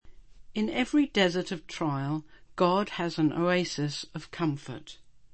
Genre: Speech.